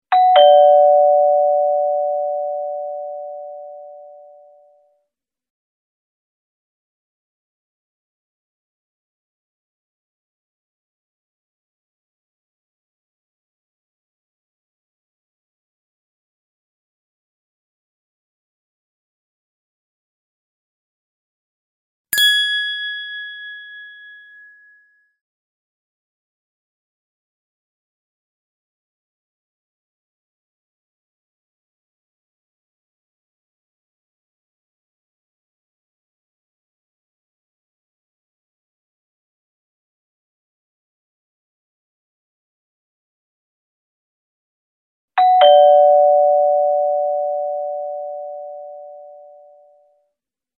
la campana suena al inicio, a los 22 segundos y a los 45 seg.